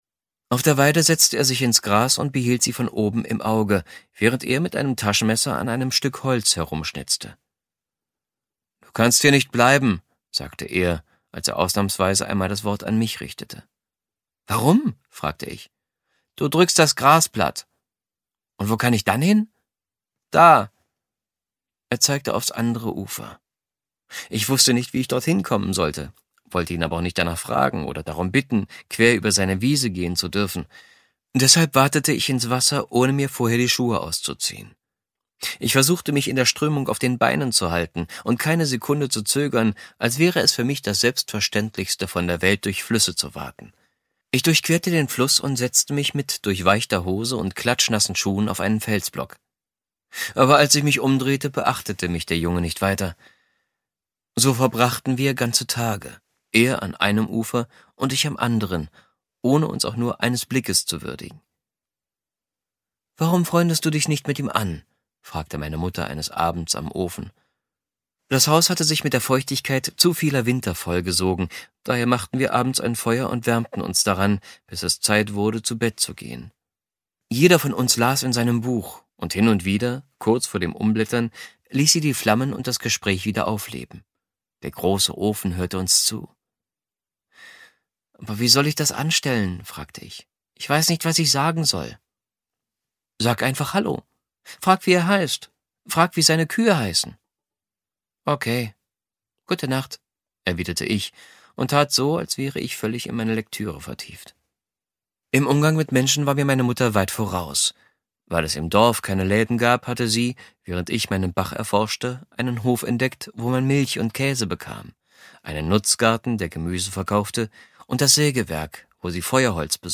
Ungekürzte Ausgabe